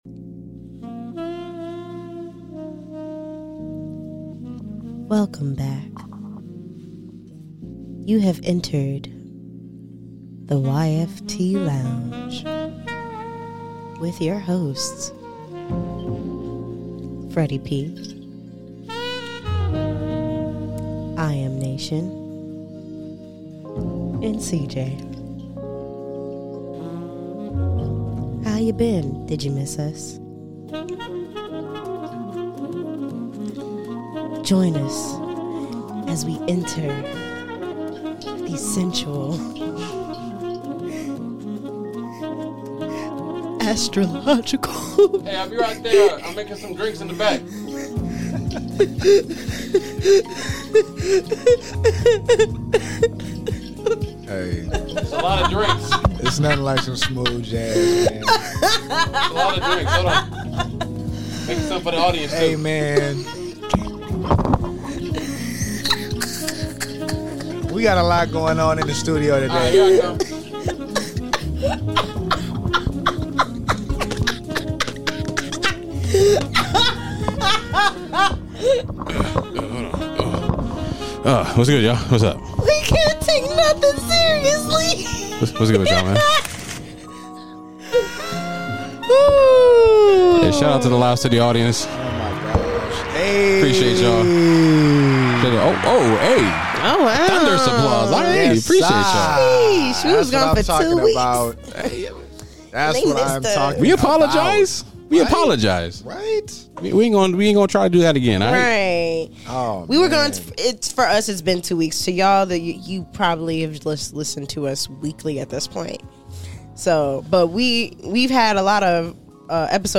This week the crew talks about how to navigate and the importance of platonic Friendships, we break the ice talking about Ocean Gate and conspiracy theories. Join us for a great conversation with Your Favorite Trio!!